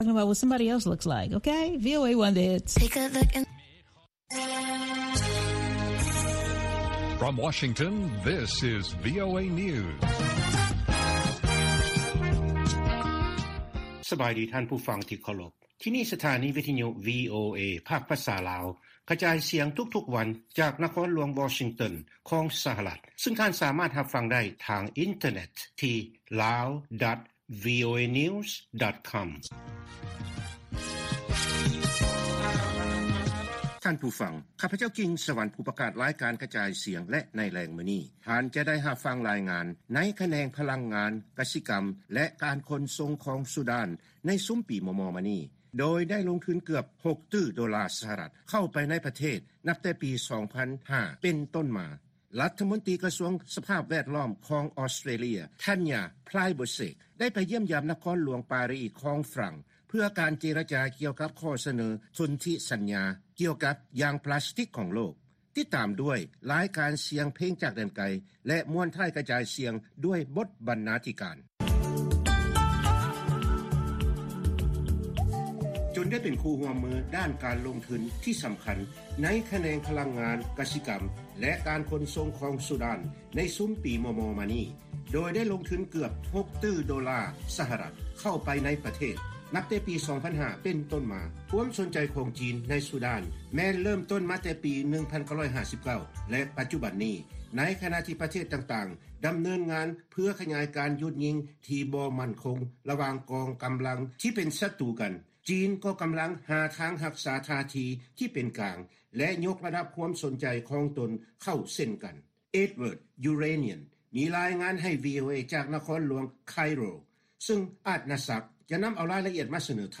ລາຍການກະຈາຍສຽງຂອງວີໂອເອ ລາວ: ຈີນສະແຫວງຫາການພົວພັນທີ່ເຂັ້ມແຂງຂຶ້ນ ກັບຊູດານ ທ່າມກາງ ການແກ່ງແຍ້ງໃນພາກພຶ້ນ ແລະ ສາກົນ